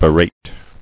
[be·rate]